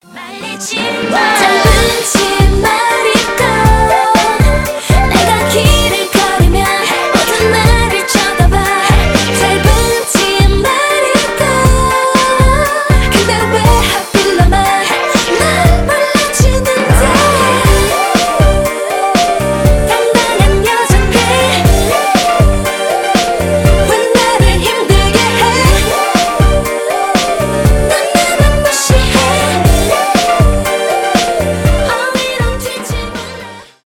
поп , k-pop